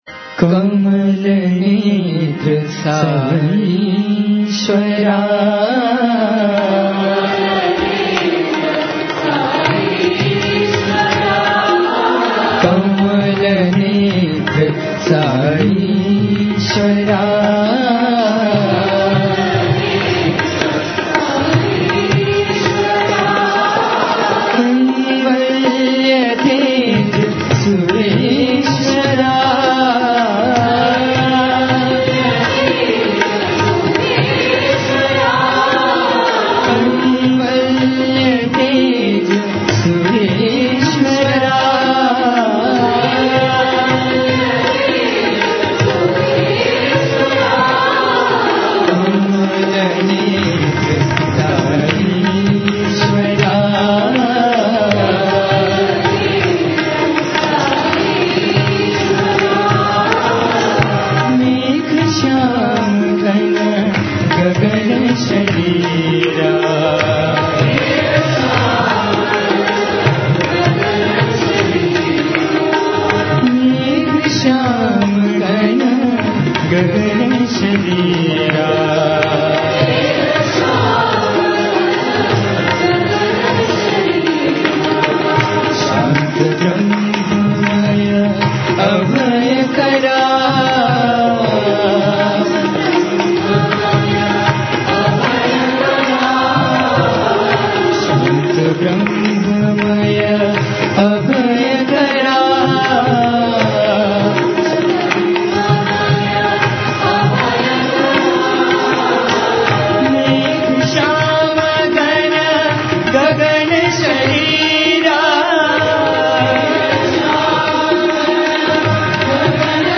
Бхаджан на день